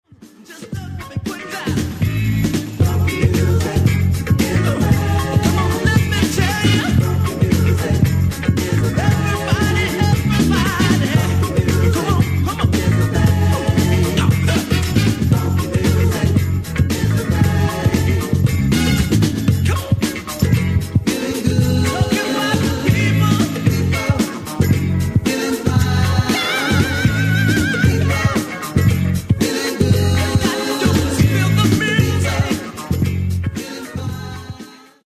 Genere:   Soul | Disco | Funky